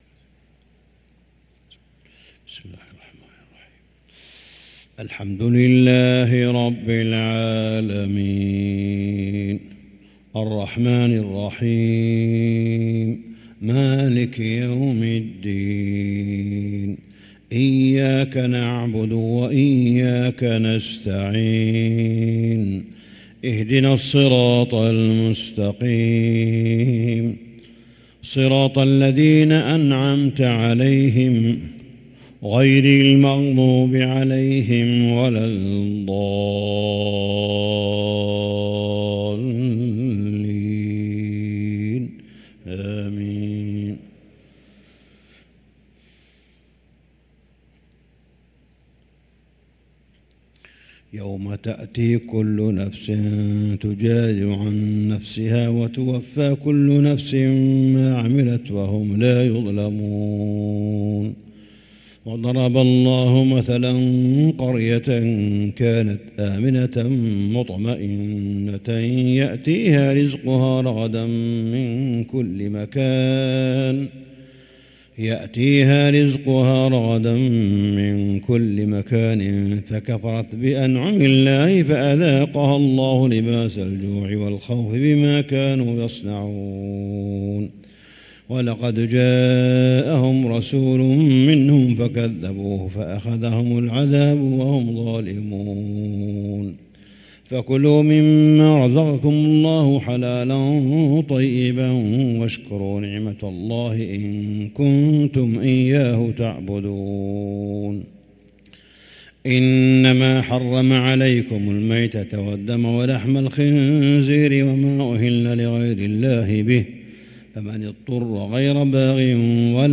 صلاة الفجر للقارئ صالح بن حميد 12 رمضان 1444 هـ
تِلَاوَات الْحَرَمَيْن .